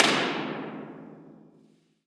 Concrete, peeling paint, metal.
Download this impulse response (right click and “save as”)